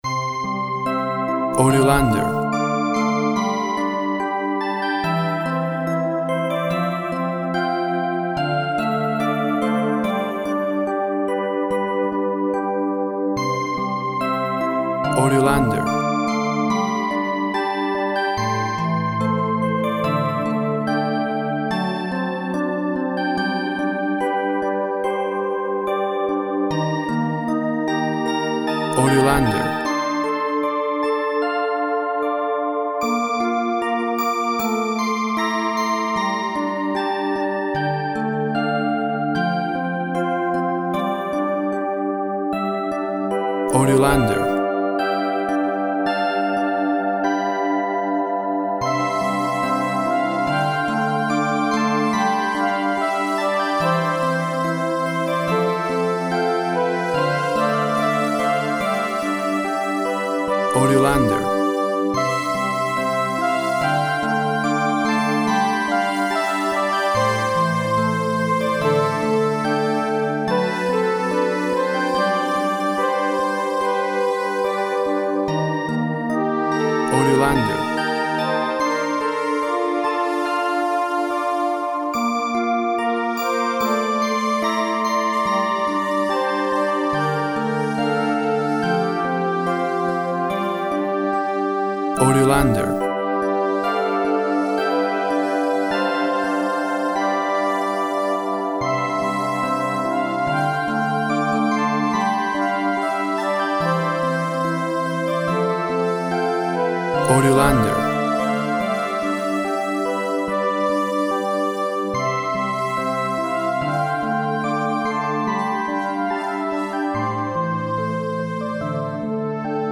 Childlike simplicity expressed with synths.
Tempo (BPM) 72